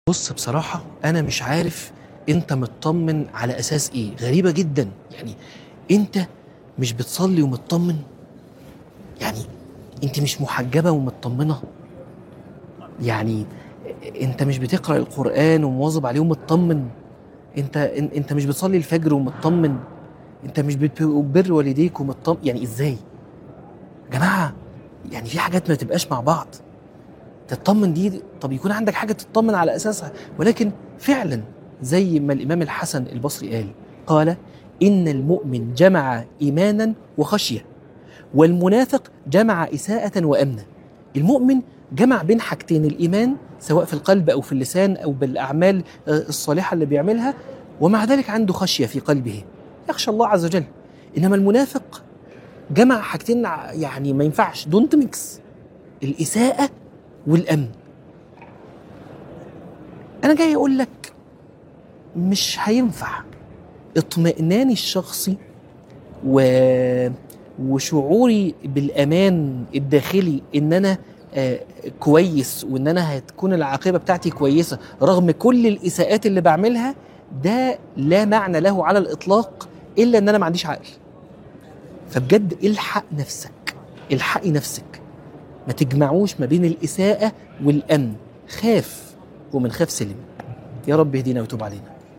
عنوان المادة متطمن على أساس أيه؟ - من الحرم